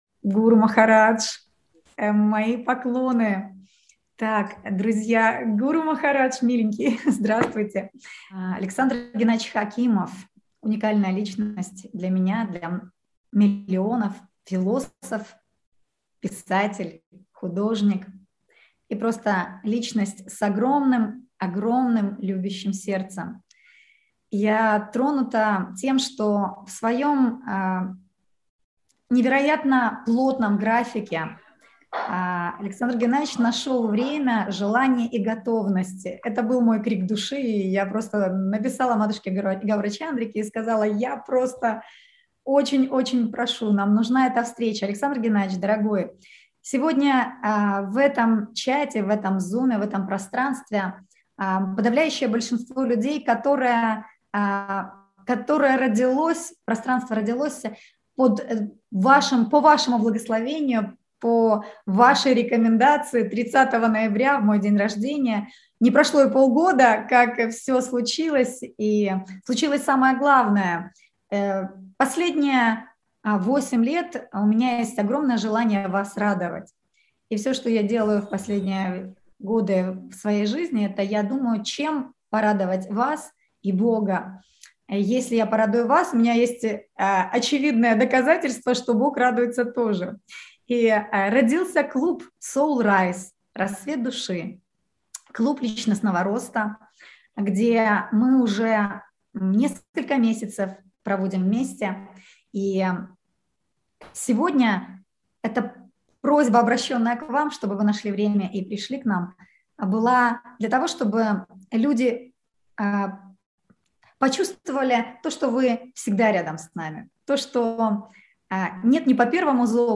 Горячий Ключ